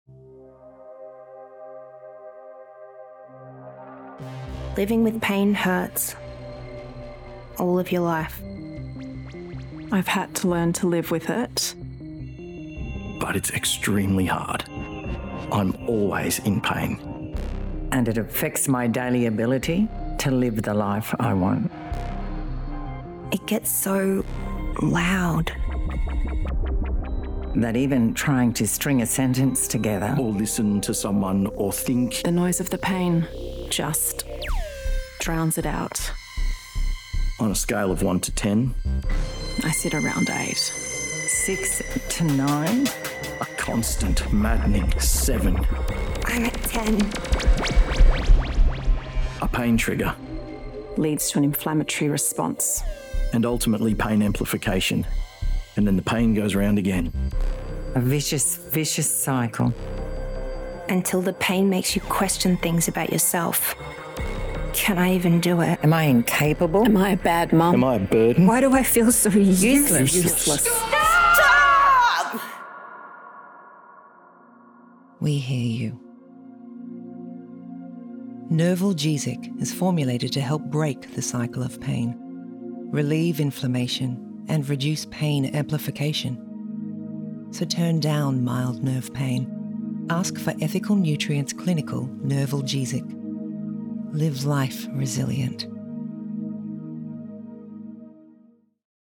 composing a music track to not only score
scale from 1 to 10. Audio volume is too.